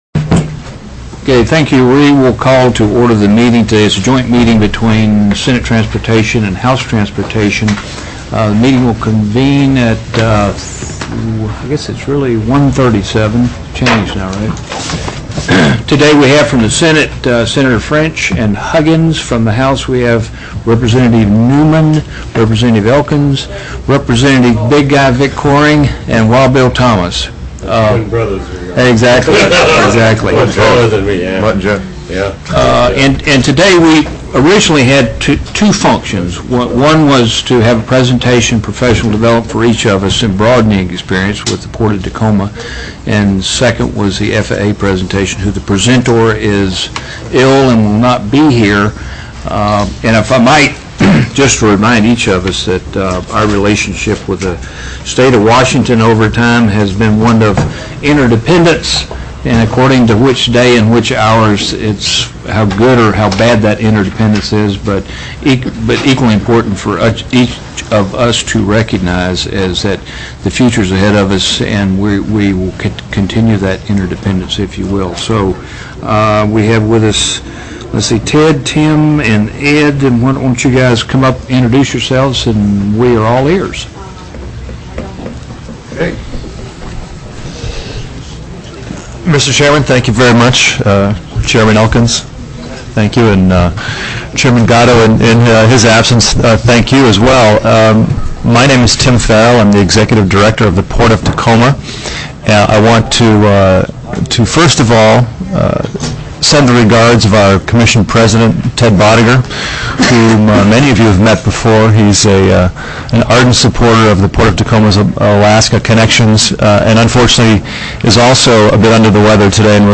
04/05/2005 01:30 PM House TRANSPORTATION